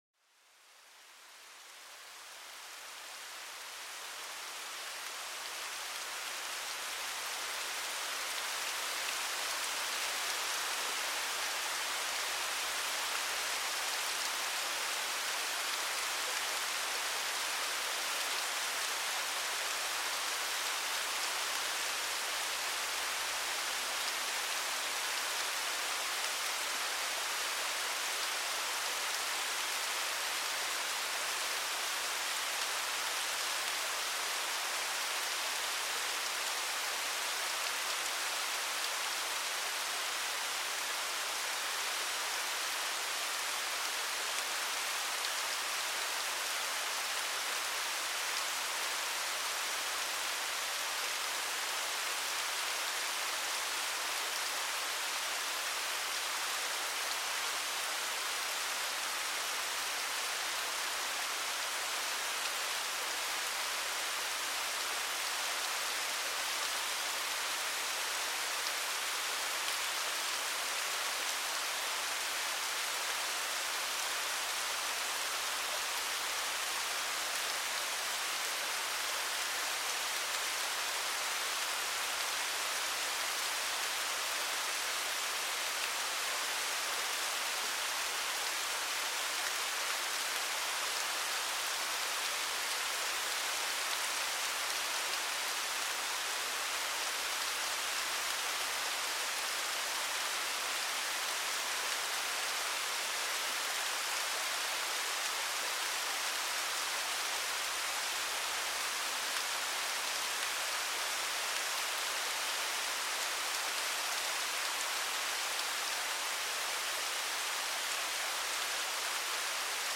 Cascade apaisante : le flux qui calme l'esprit
Laissez-vous emporter par le doux tumulte d'une cascade naturelle, où l'eau s'écoule en un rythme apaisant. Chaque goutte qui tombe crée une harmonie propice à la détente et à l'évasion mentale.